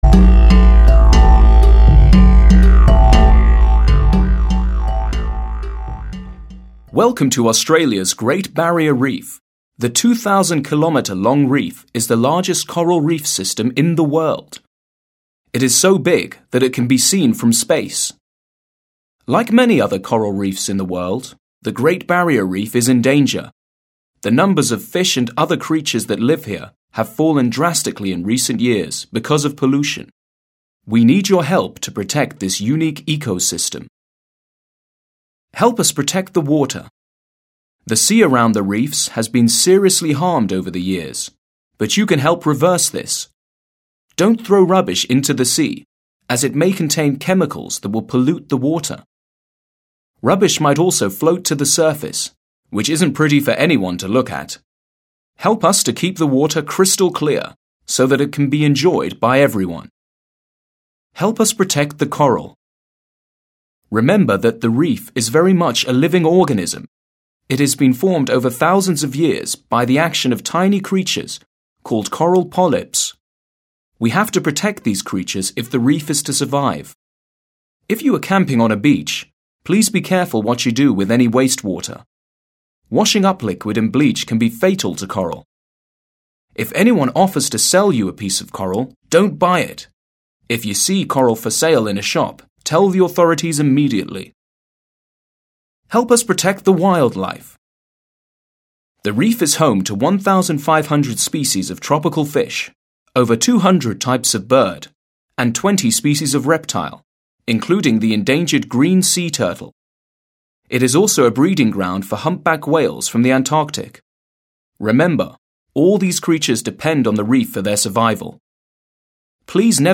4. Listening to the text about the Great Barrier Reef. Discussion (Аудирование с использованием аутентичного текста о Большом Барьерном рифе. Дискуссия)